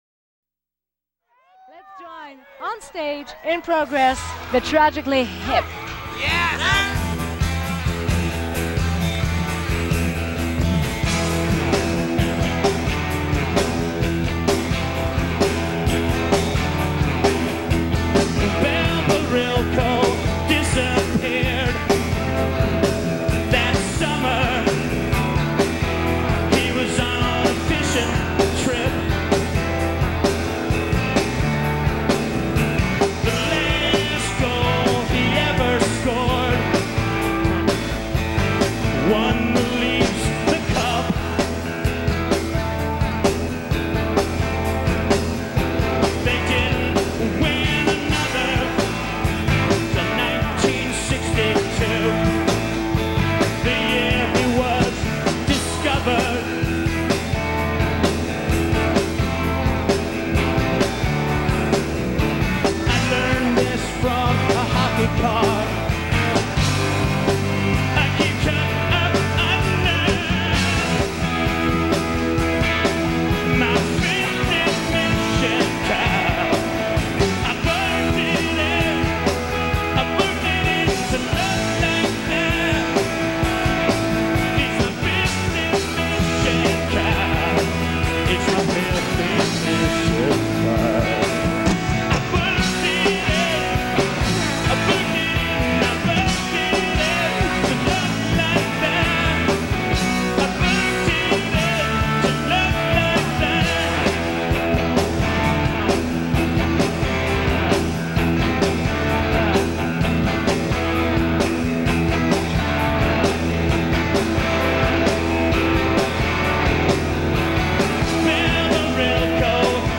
Live In 1992
Source: TV